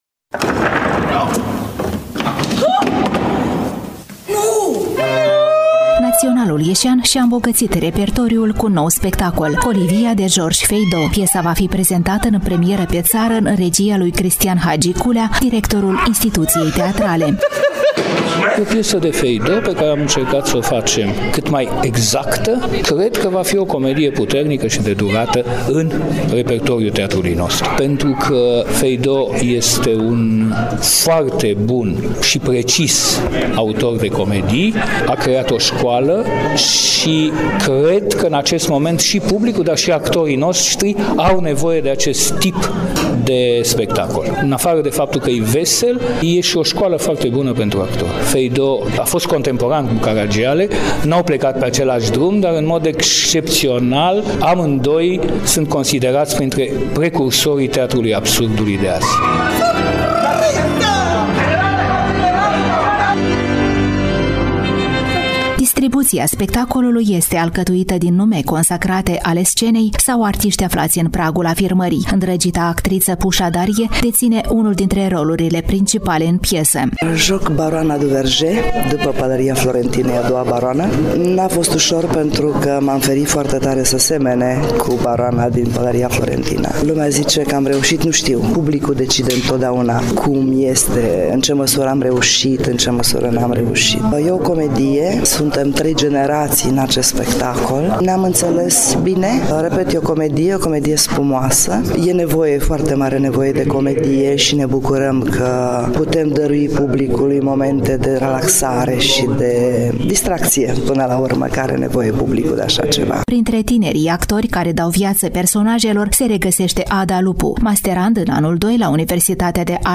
„Colivia” de Georges Faydeau, în premiera pe ţară la Naţionalul ieşean - Reportaj cultural